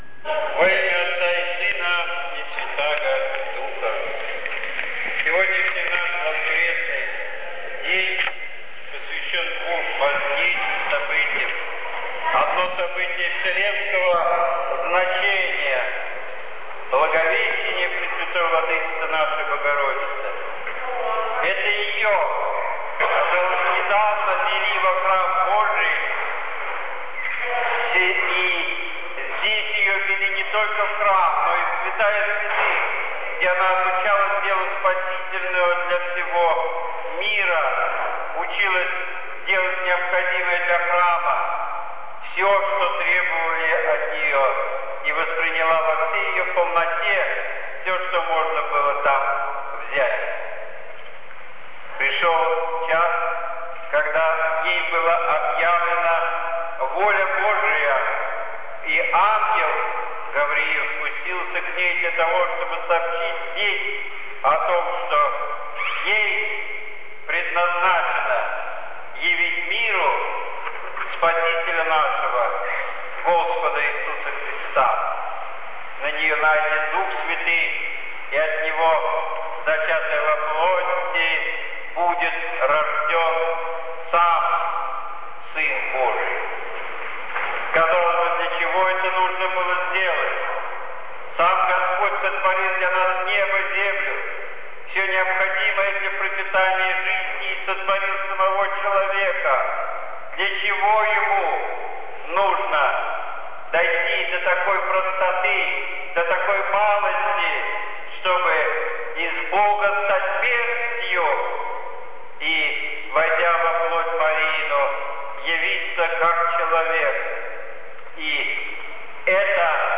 Слово священника